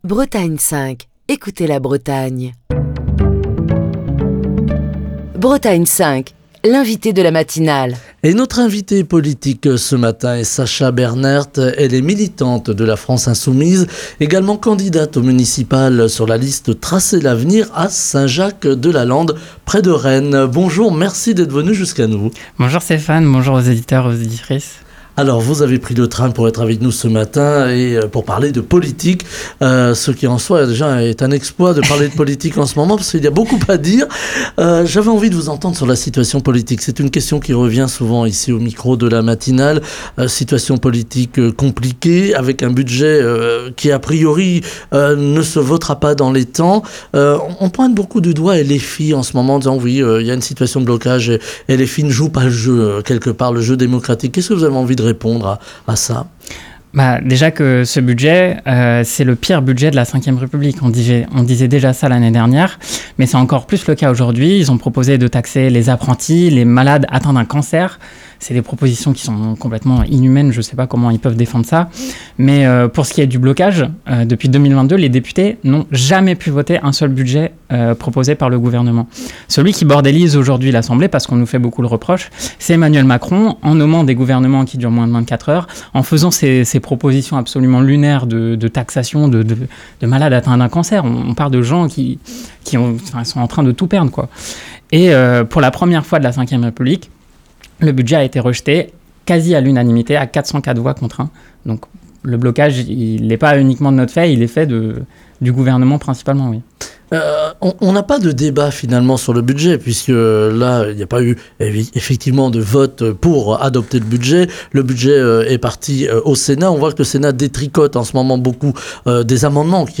dans la matinale de Bretagne 5